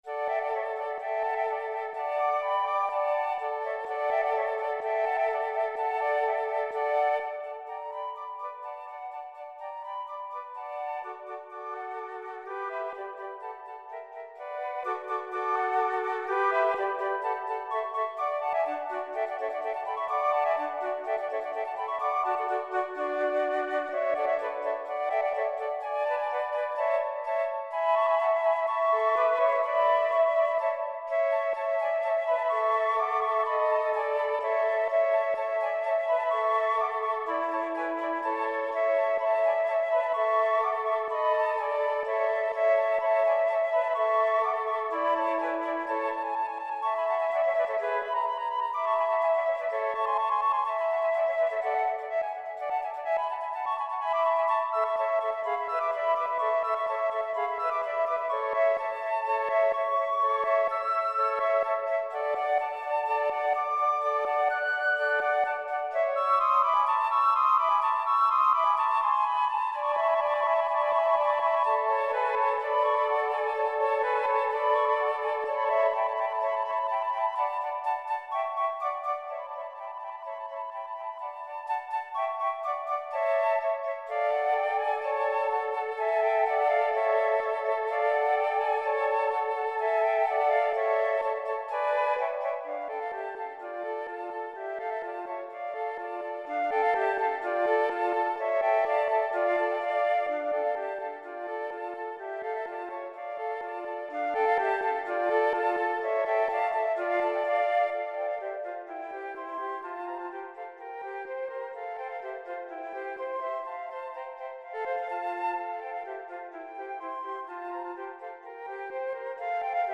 für 3 Flöten Partitur und Stimmen